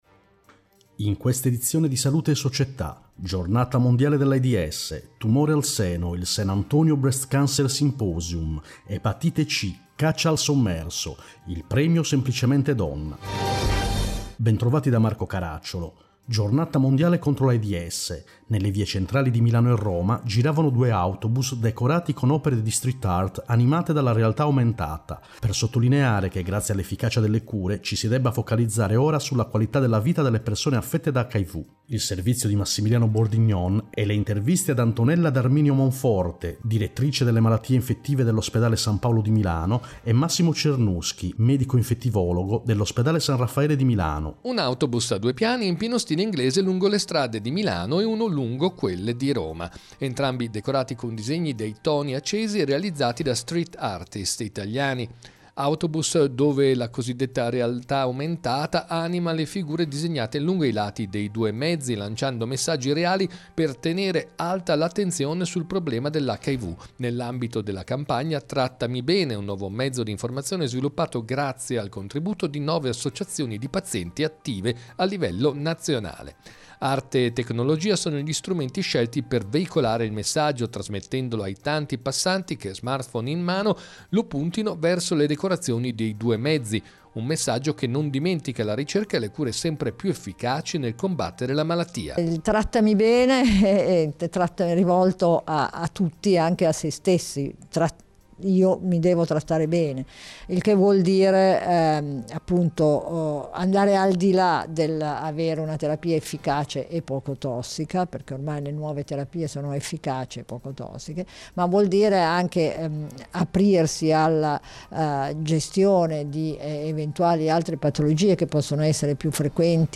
In questa edizione: 1. HIV/AIDS, Giornata mondiale 2. Tumore al seno, San Antonio Breast Cancer Symposium 3. Epatite C, Caccia al sommerso 4. Violenza, Premio Semplicemente Donna Interviste